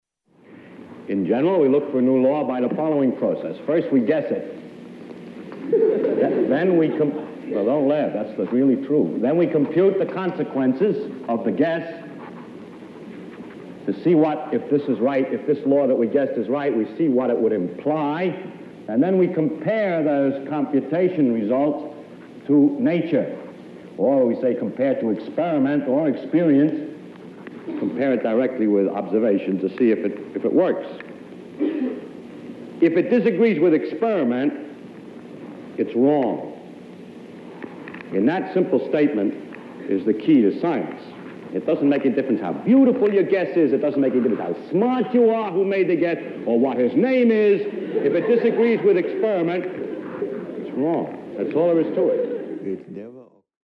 Feynman used to describe to budding scientists the rules of science.
He described the scientific process thus; "In general we look for a new law by the following process. First we guess it." At this point it was common for his students to laugh at what was obviously a Feynman joke.